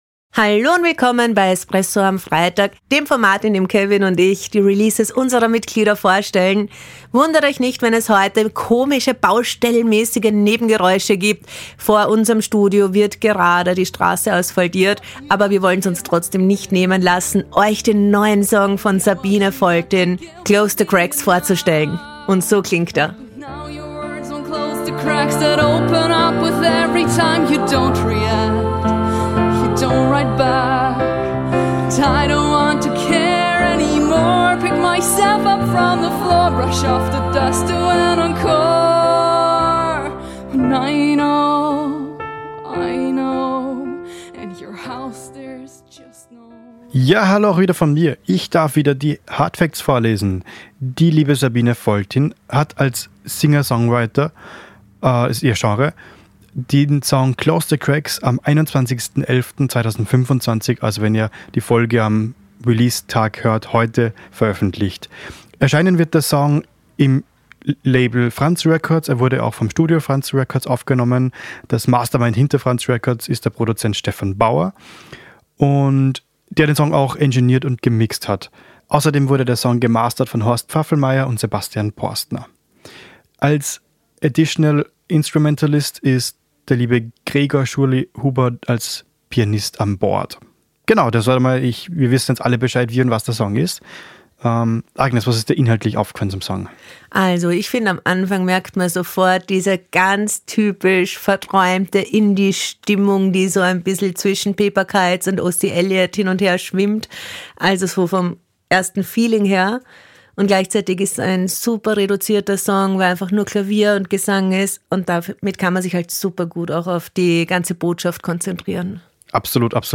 Die Release-Rezension für Newcomer
Genre: Singer-Songwriter